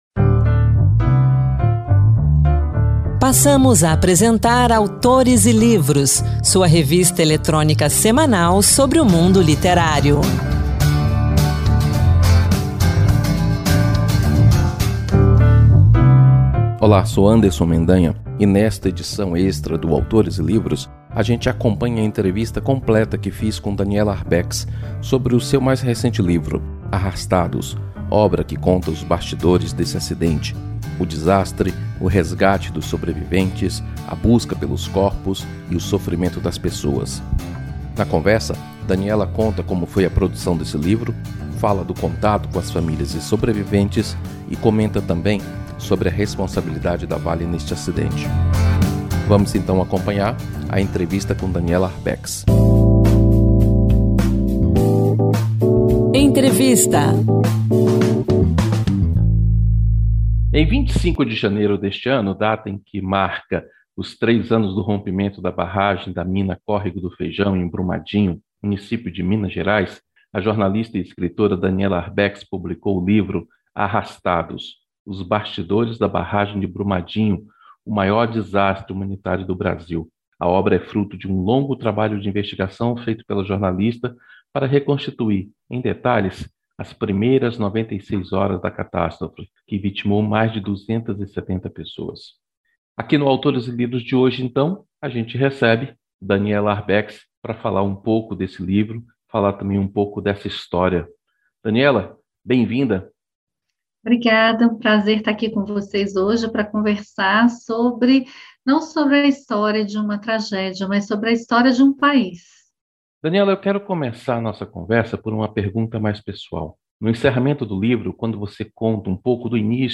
Revista literária com entrevistas com autores, poesias, dicas de livros e também notícias sobre o mundo da literatura e as últimas publicações do Senado Federal